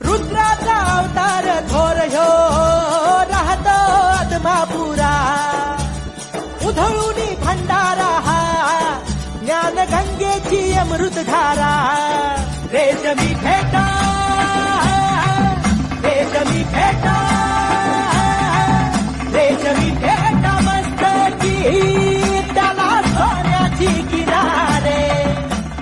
Category: Devotional Ringtones